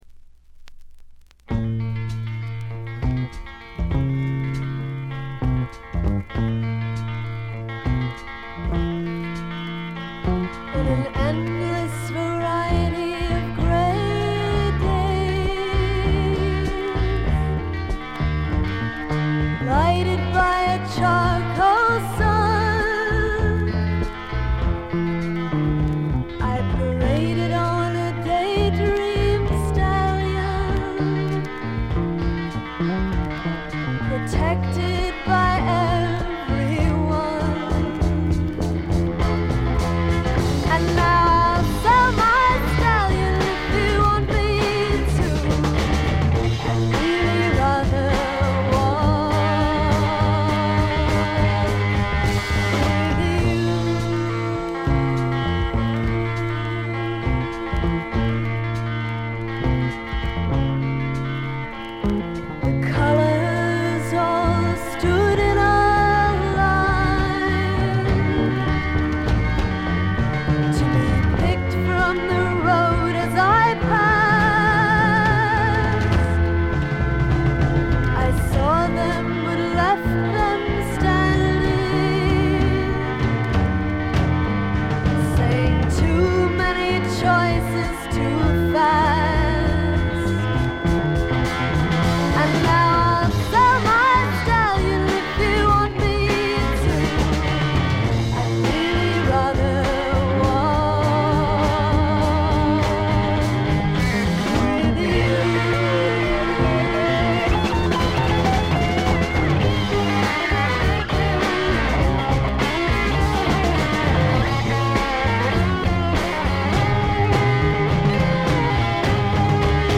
ほとんどノイズ感無し。
個人的にはギターの音色がなかなかアシッド／サイケしていてかなりつぼに来ます。
試聴曲は現品からの取り込み音源です。